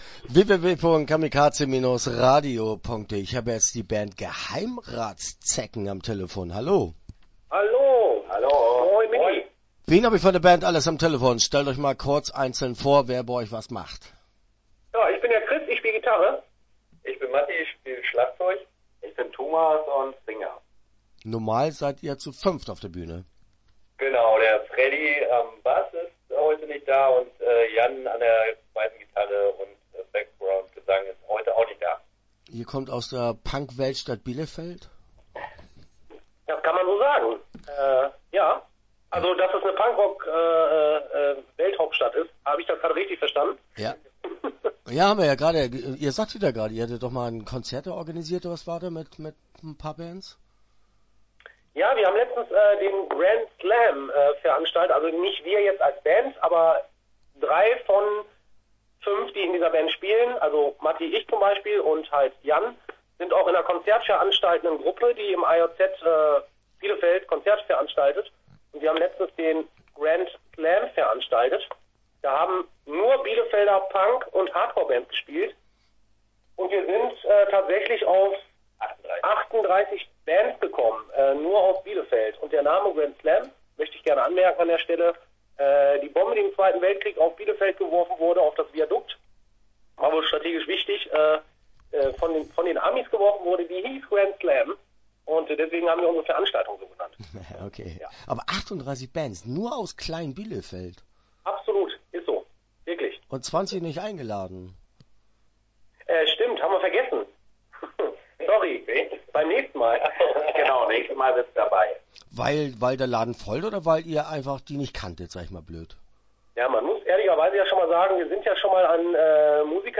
Geheimratszecken - Interview Teil 1 (11:37)